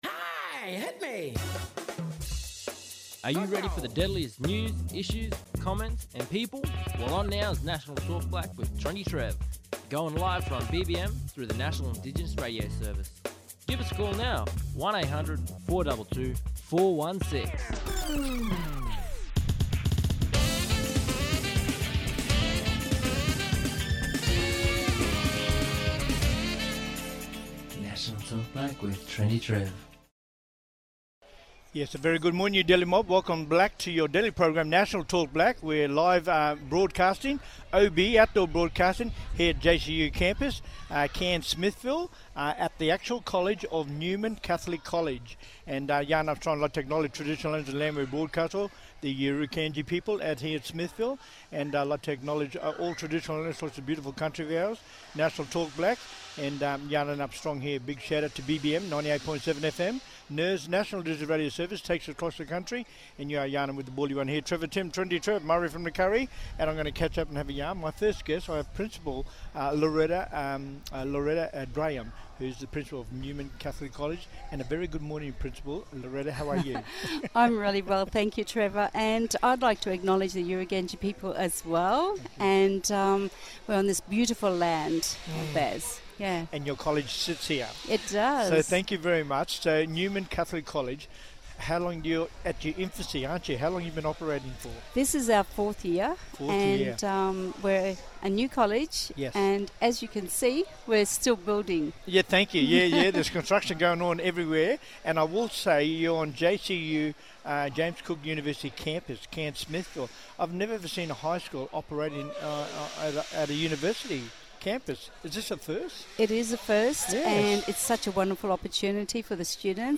Special Guests Live today from Newman Catholic College, Smithfield for their 2025 NAIDOC Celebrations.